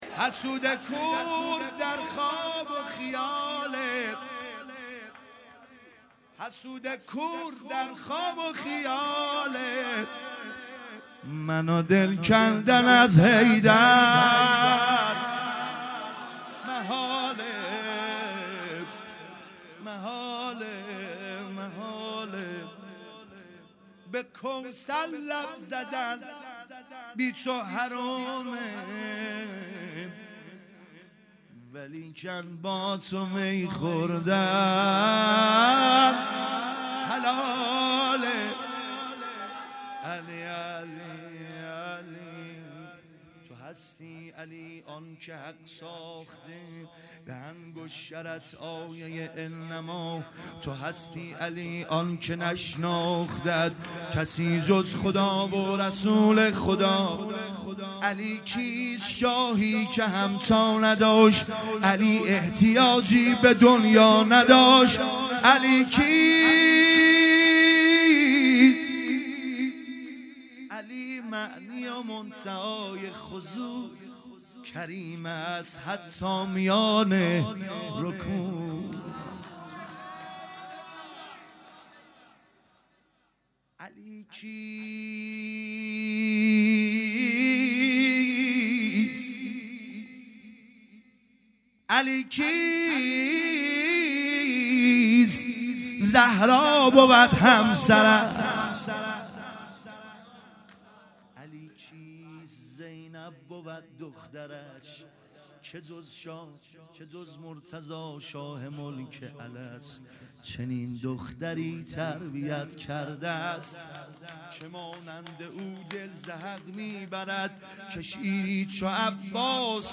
مدح
دهه سوم محرم الحرام ۹۷ | ۱۰ مهر ۹۷